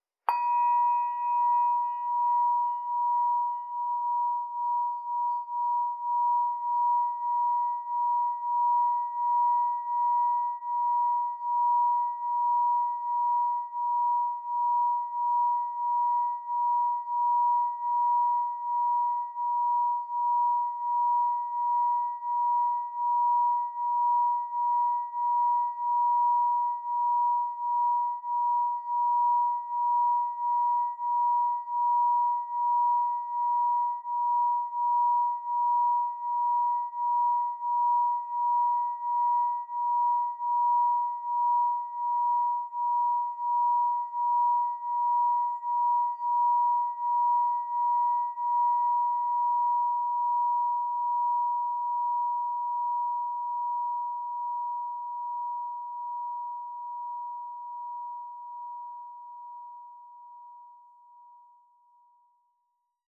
Meinl Sonic Energy 3.25" Essence Solfeggio Crystal Singing Bowl 963 Hz, Coral Reef (ESOLCSB963)